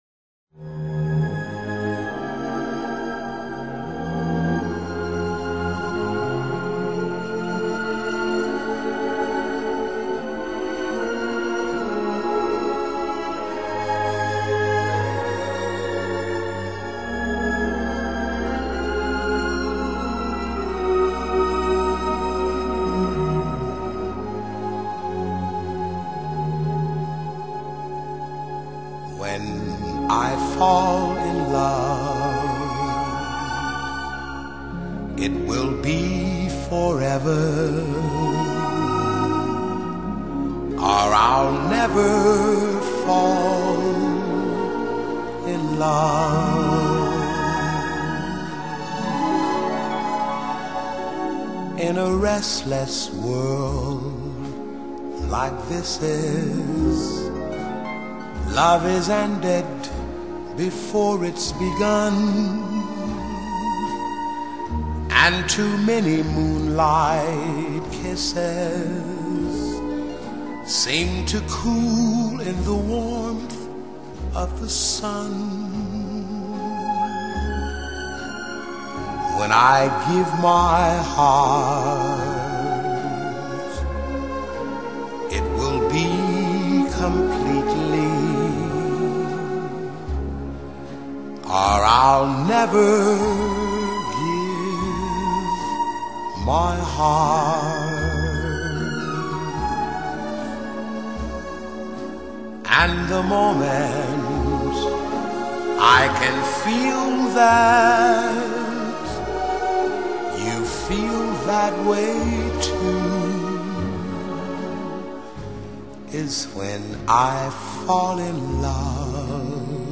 Genre:Pop/Easy listening